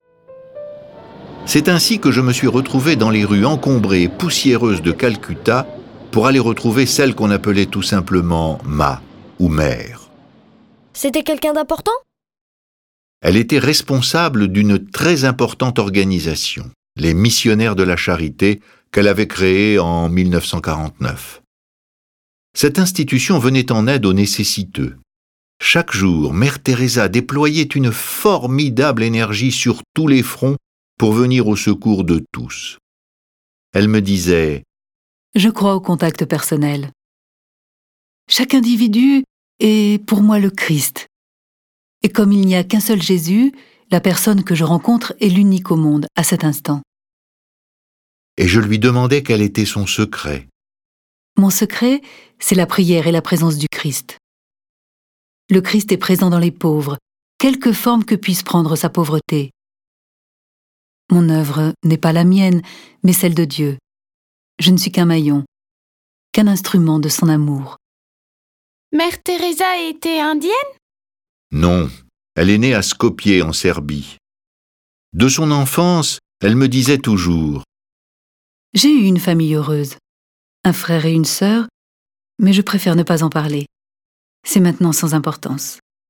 Diffusion distribution ebook et livre audio - Catalogue livres numériques
Cette version sonore du récit de sa vie est animée par dix voix et accompagné de plus de 30 morceaux de musiques classique.